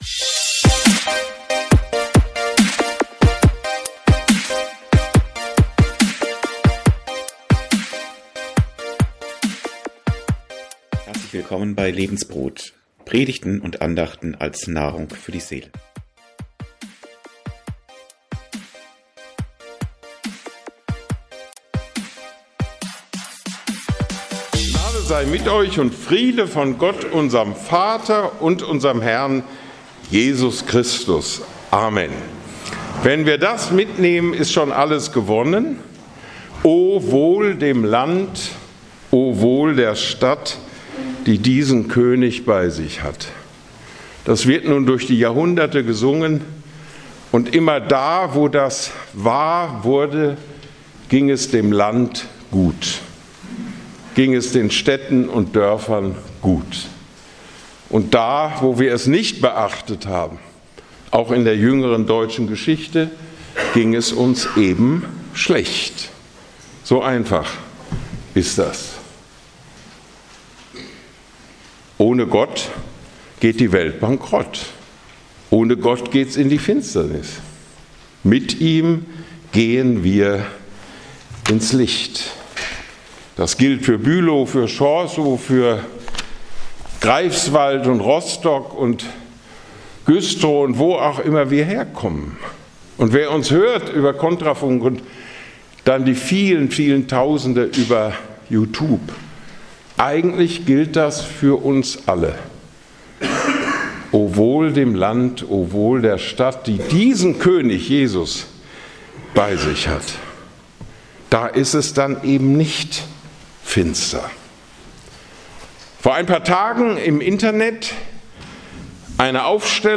Predigt von Peter Hahne in der Ev. Kirche Bülow vom 8.12.2024. Folge direkt herunterladen